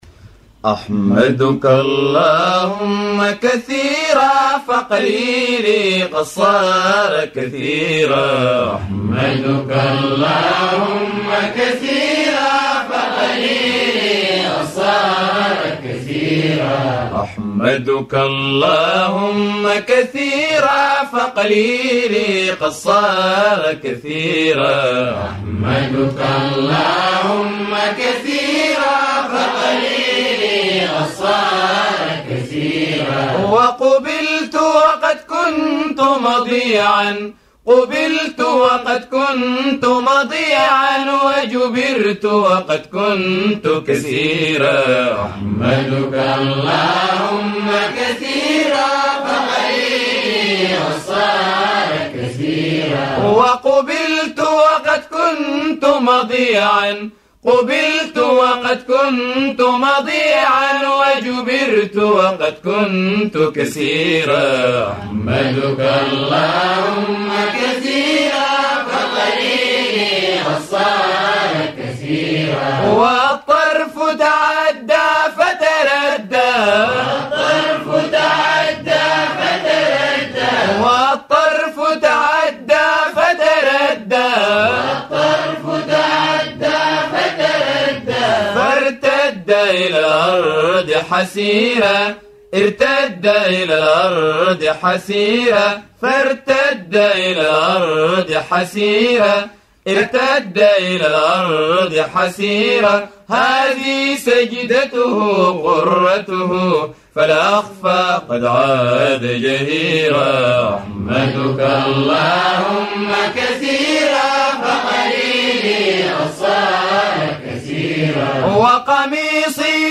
إنشاد مجموعة كسلا